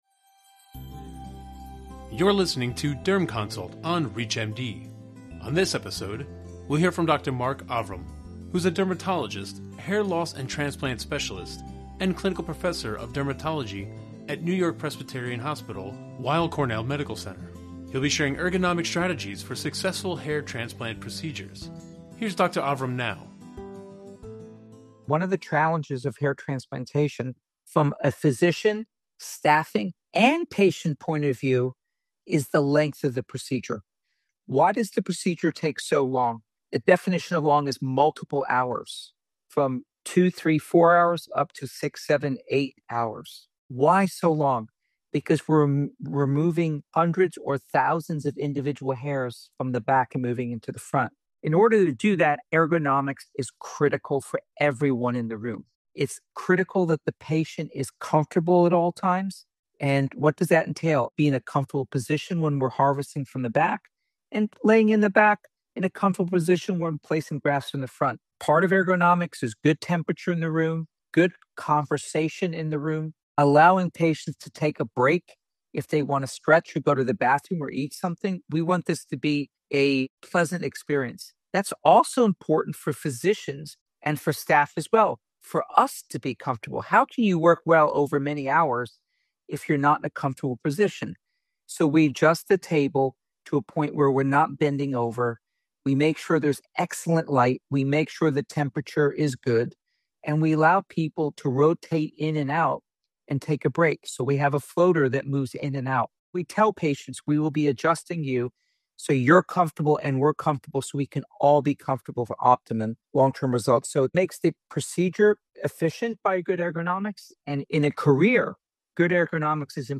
In this special series, dermatology specialists share their expert advice on the diagnosis and treatment of a variety of skin conditions.